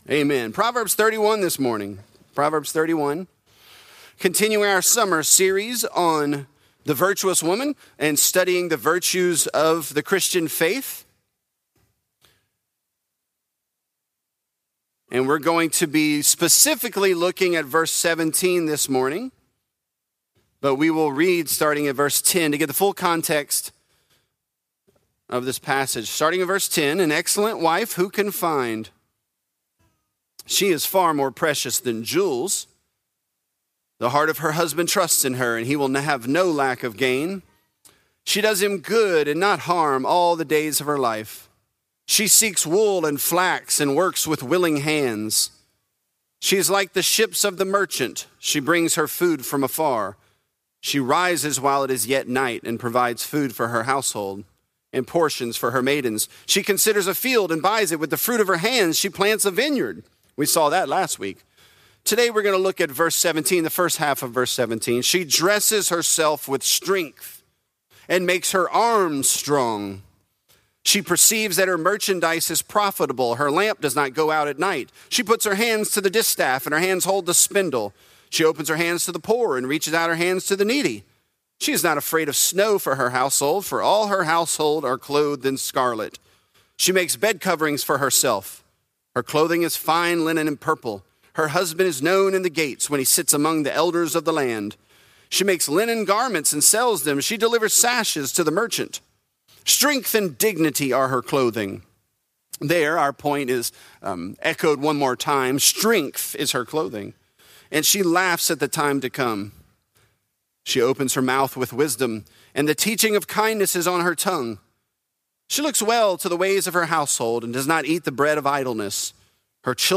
This is a part of our sermon series, "Virtuous."